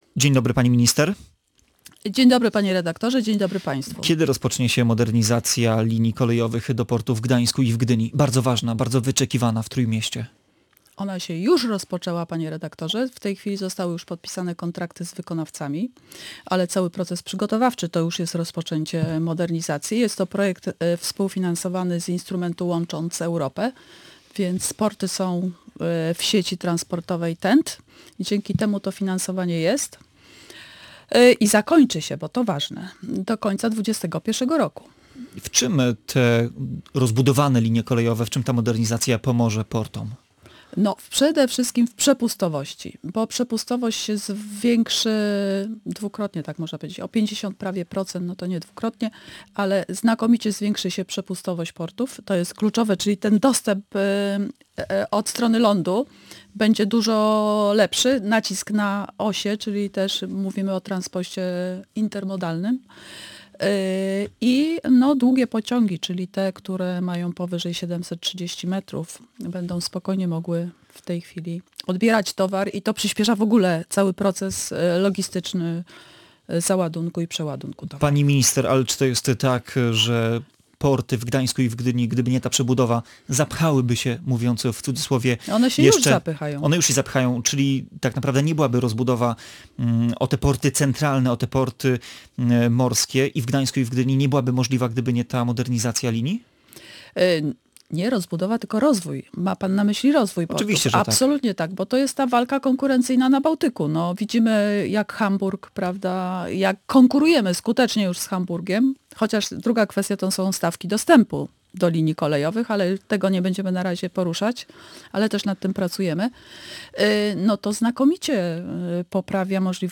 W rozmowie pojawił się także wątek Obwodnicy Metropolitalnej.